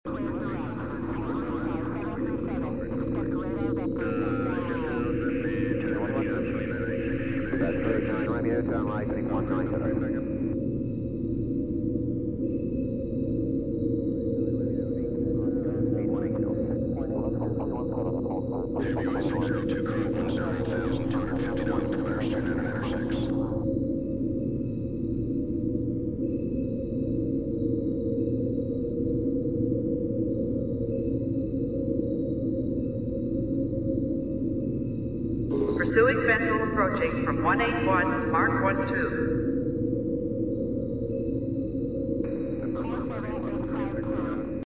controlroom.mp3